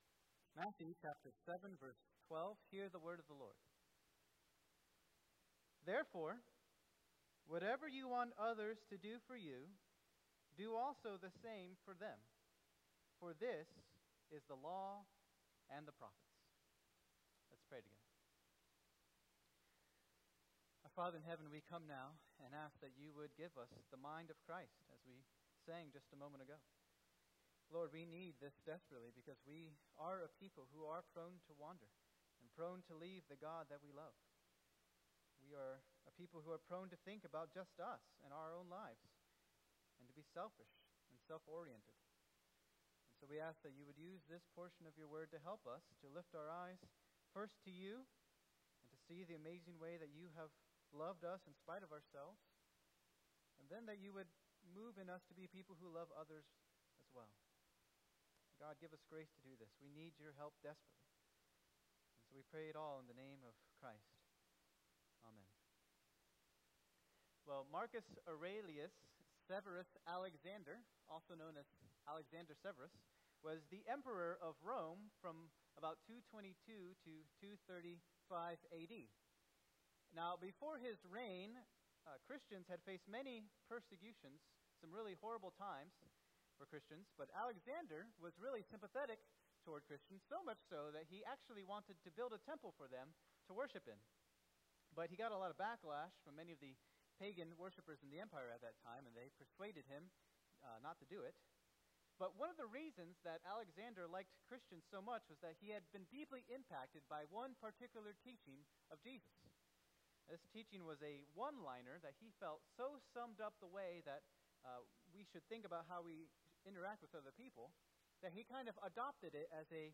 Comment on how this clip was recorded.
2024 at First Baptist Church in Delphi